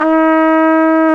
Index of /90_sSampleCDs/Roland L-CD702/VOL-2/BRS_Tpt 5-7 Solo/BRS_Tp 6 AKG Jaz